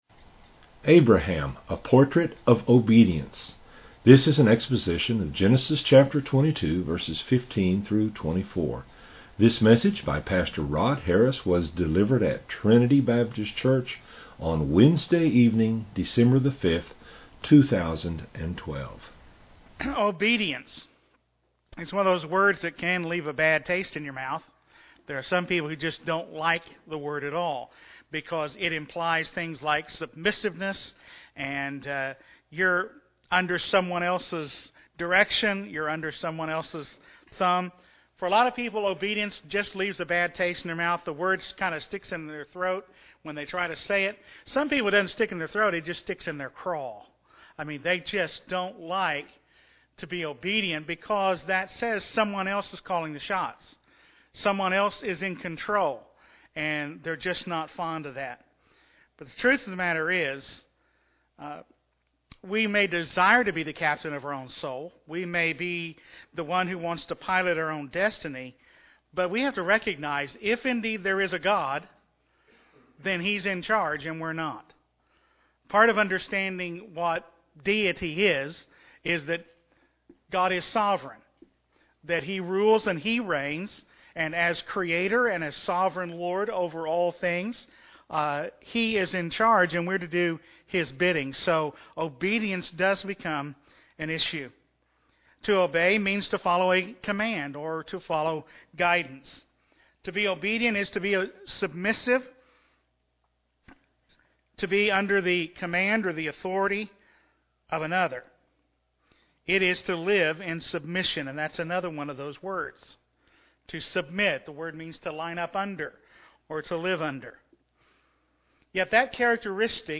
delivered at Trinity Baptist Church on Wednesday evening, December 5, 2012.